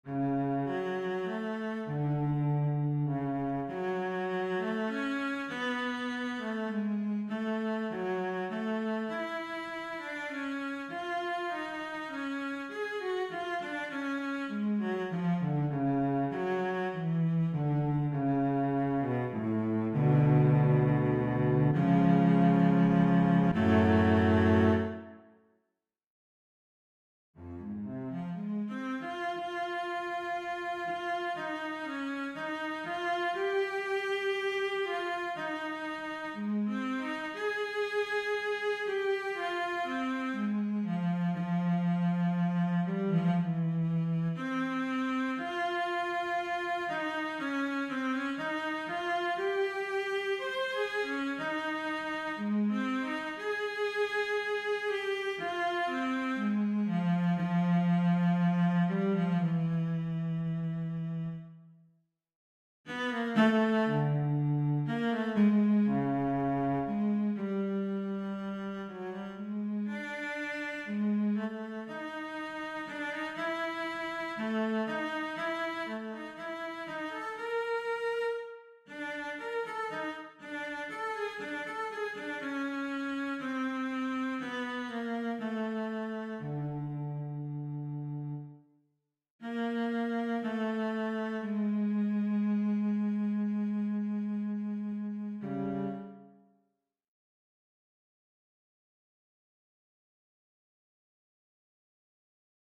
Voicing: Cello